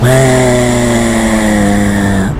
WooooooW Sound Effect